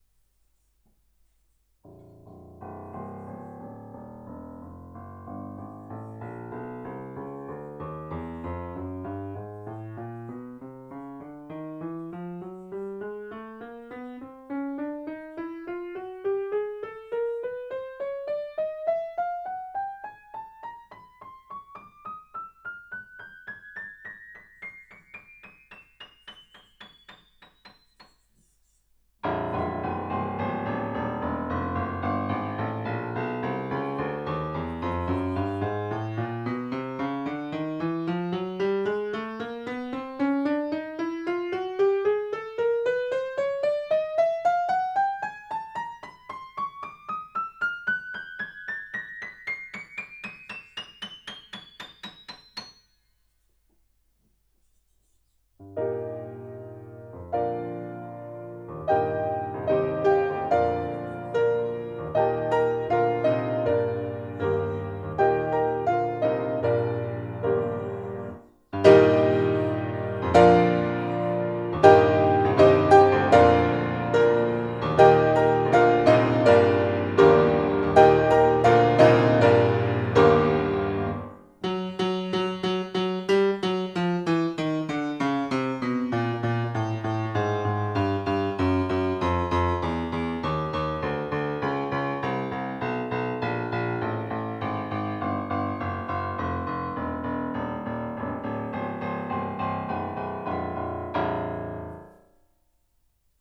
Vibration parasite dans un Yamaha S400B récemment refait
Trouvant que le son devenait pénible, avec des harmoniques aigües désagréables - comme un son de papier froissé qui se superposerait au son normal du piano, quand on joue "forte" - je l'ai fait expertiser par trois techniciens réparateurs.
Au début, je parcours tout le piano nuance p, puis la même chose nuance f. Ensuite, je fait un petit morceau p et le même ff. Enfin, j'insiste sur quelques notes où le défaut est le plus marqué.
Bon à part des unissons défectueux on entend pas grand chose ....
Effectivement cela zingue à quelques endroits lorsque l'on joue fort (notamment medium grave).
Si, entre 1:10 et 1:30, et c'est encore plus patent en direct, par rapport à l'enregistrement...